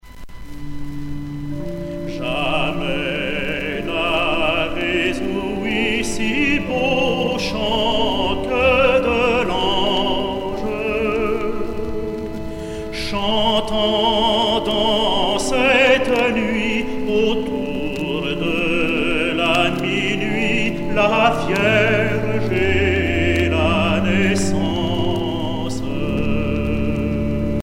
Noël, Nativité
Genre strophique
Pièce musicale éditée